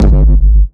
GS Phat Kicks 001.wav